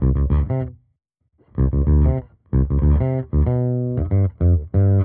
Index of /musicradar/dusty-funk-samples/Bass/95bpm
DF_JaBass_95-D.wav